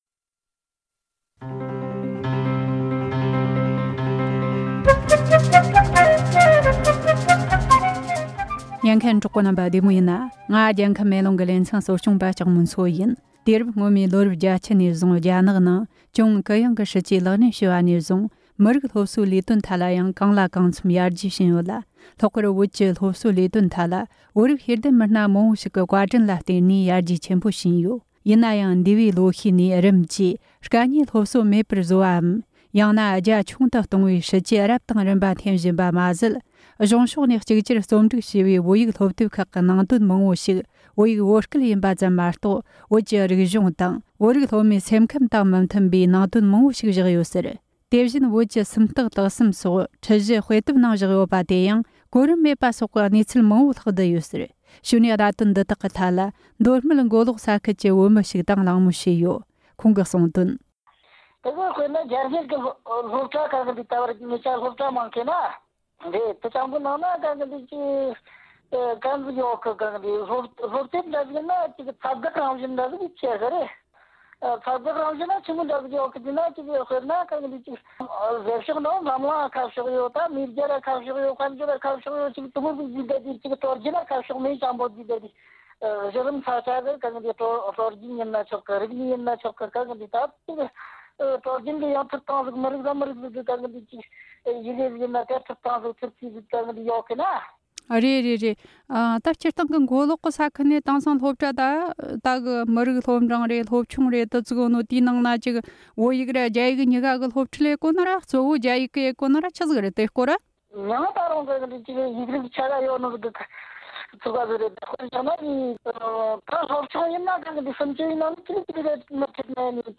བོད་ནང་གི་སློབ་གྲྭ་ཆུ་འབྲིང་གི་སློབ་ཚན་སྐོར་གླེང་མོལ།
སྒྲ་ལྡན་གསར་འགྱུར། སྒྲ་ཕབ་ལེན།